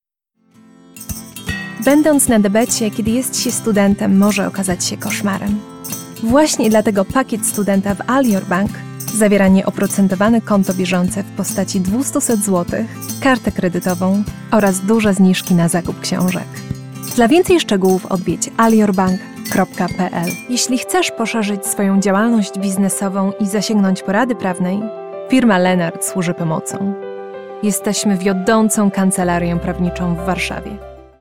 Étouffant
Amical
De la conversation